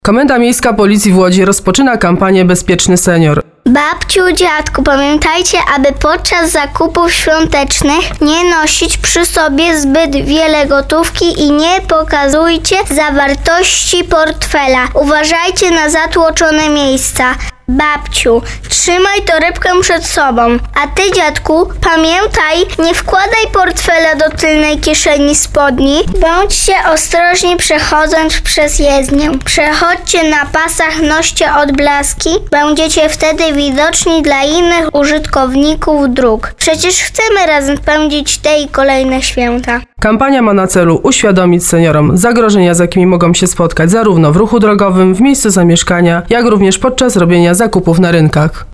Funkcjonariusze Wydziału Prewencji KMP w Łodzi w ramach kampanii „Bezpieczny senior” przygotowali komunikat głosowy i ulotkę, przestrzegające seniorów przed zagrożeniami, z którymi mogą się spotkać podczas poruszania się po drogach. czy w trakcie zakupów świątecznych i podróży w środkach komunikacji miejskiej.